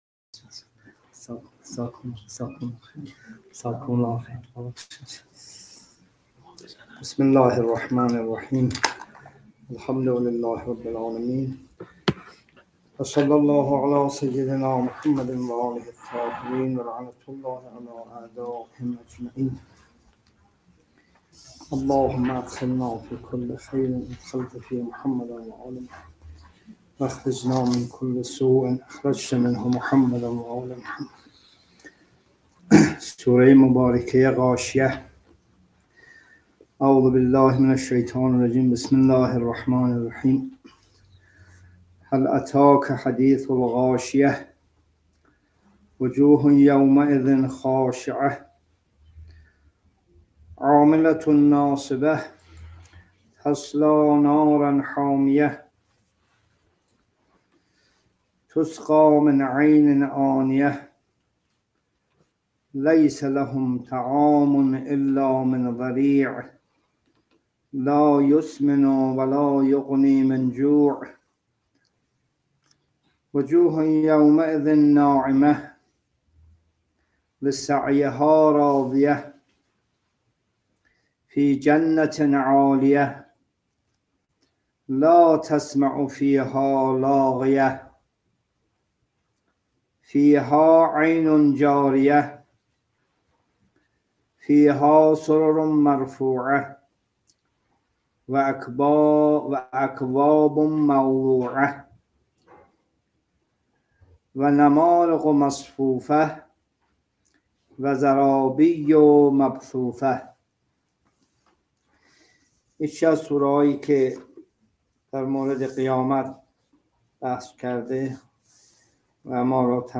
جلسه تفسیر قرآن